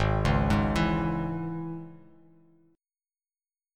Absus4#5 chord